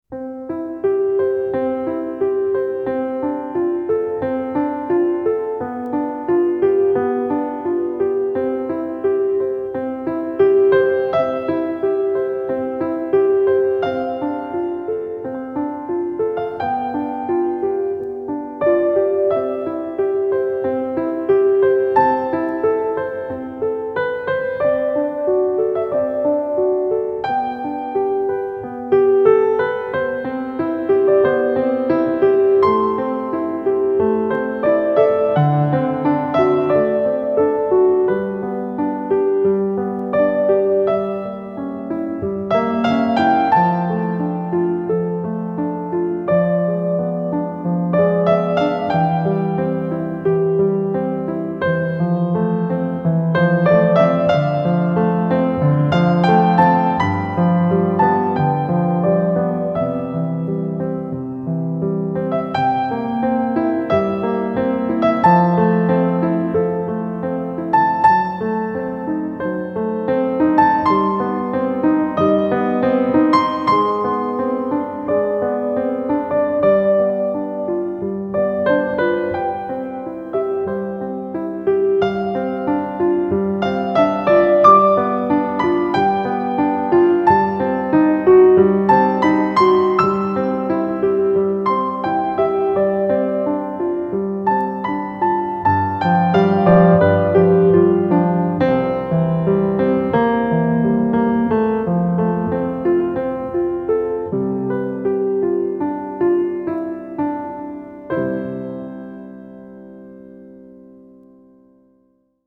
лучшая классическая музыка